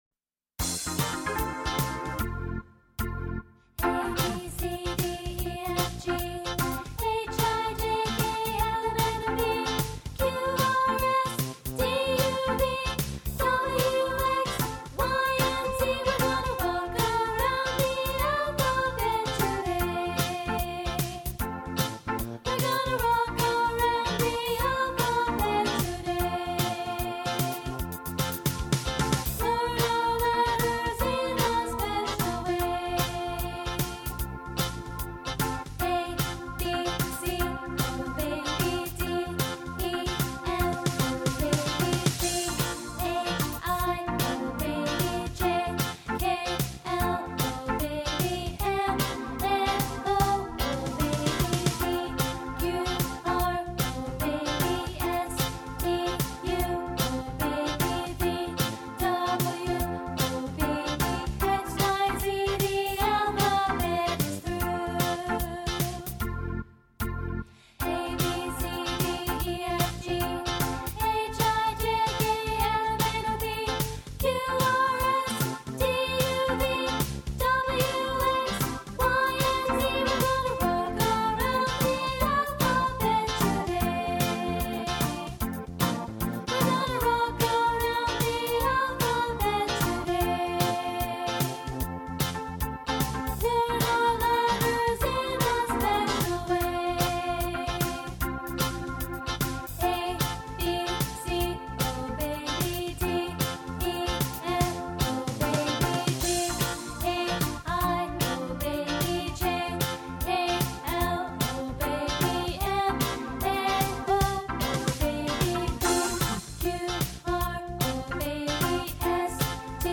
Voicing: Unison